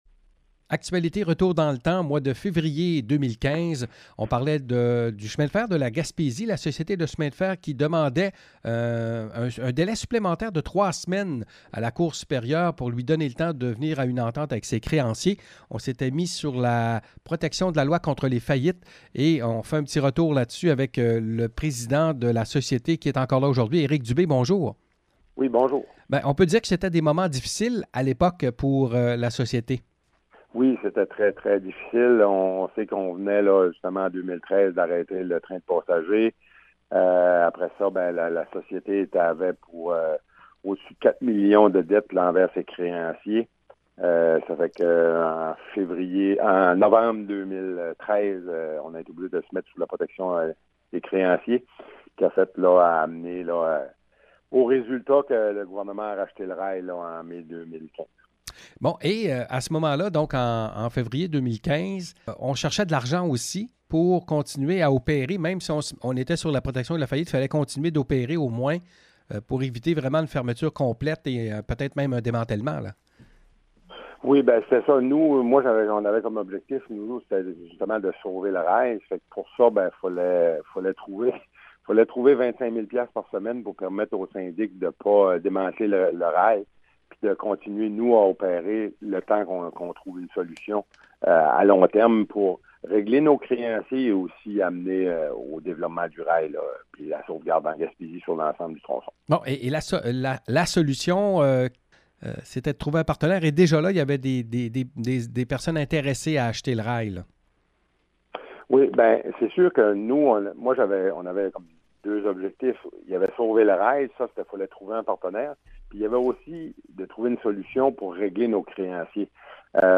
En 2015, la Société du chemin de fer de la Gaspésie est au bord de la faillite et il y a risque de démantèlement du rail. Entrevue